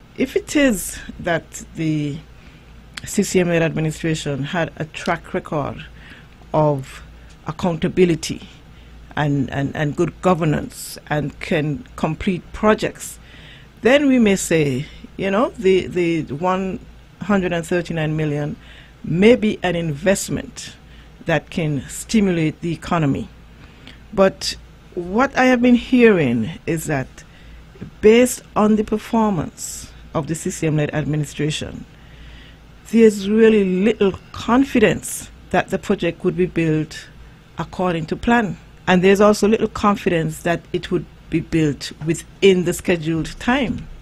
During a discussion on VON Radio surrounding the interest of Nevis, Political Leader of the NRP and Parliamentary Representative of Nevis #4, St. James’ Parish, Hon. Dr. Janice Daniel Hodge and Deputy Leader, Dr. Patricia Bartlette, claimed that a mismanagement of funds and prolonged time had occurred with other projects including the new wing of the Alexandra Hospital and the Newcastle Police Station.